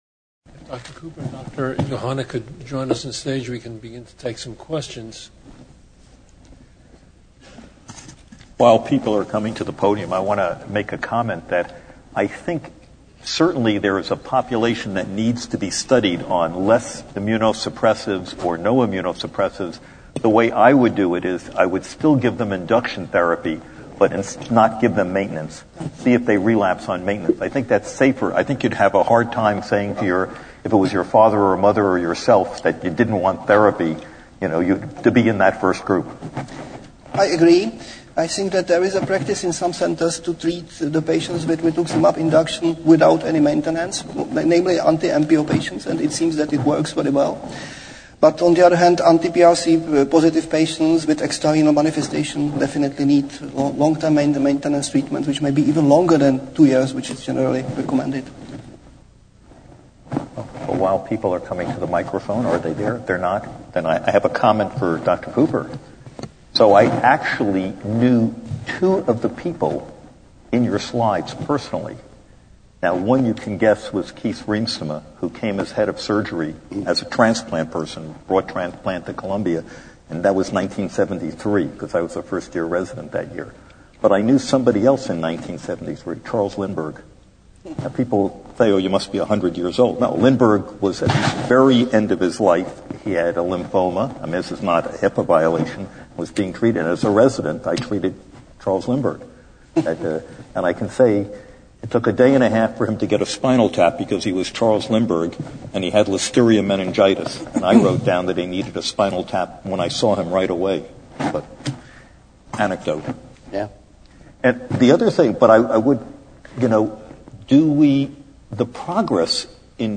Discussions